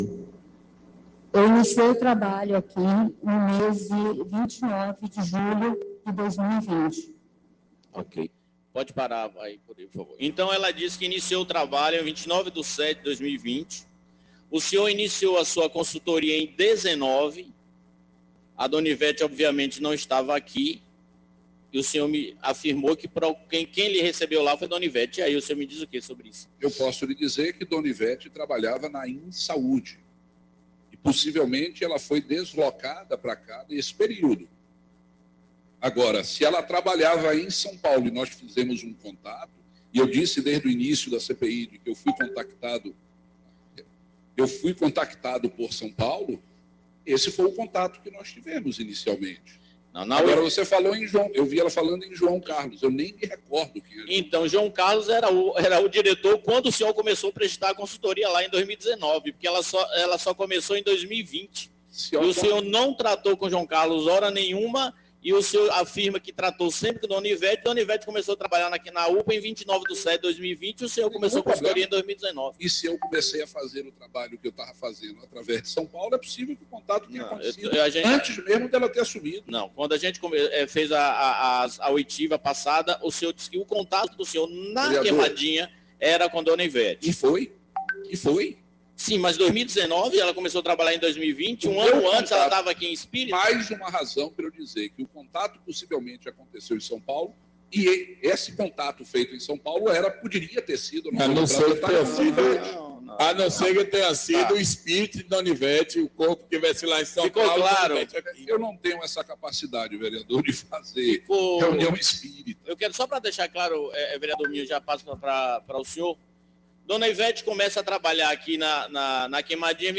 O secretário foi desmoralizado no plenário da Câmara ao mentir no depoimento da CPI nesta terça-feira (12).
Ouçam os áudios no momento em que Dr. Marcelo teria mentido na CPI e foi duramente advertido pelos edis.